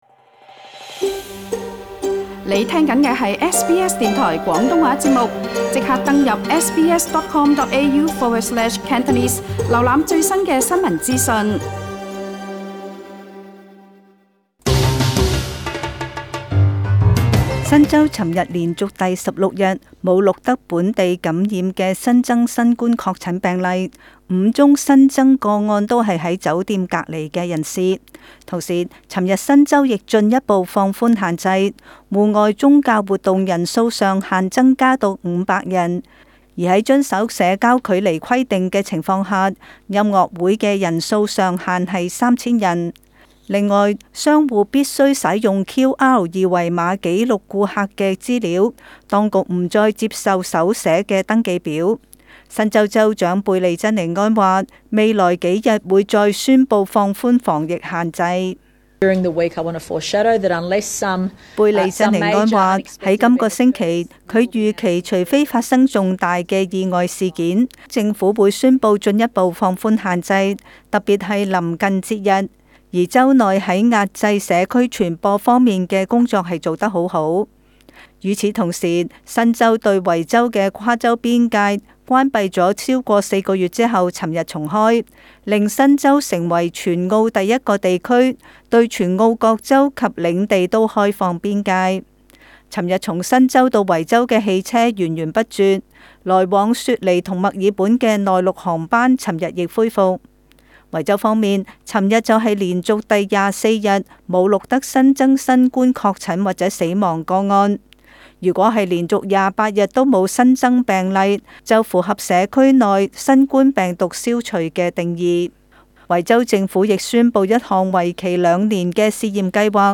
【時事報導】